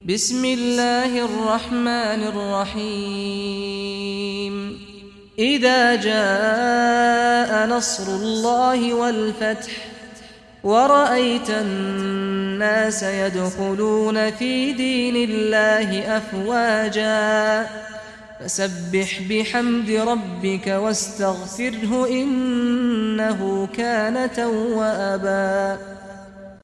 Surah An-Nasr Recitation by Sheikh Saad Ghamdi
Surah An-Nasr, listen or play online mp3 tilawat / recitation in Arabic in the beautiful voice of Sheikh Saad al Ghamdi.